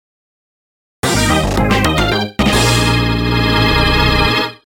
The fanfare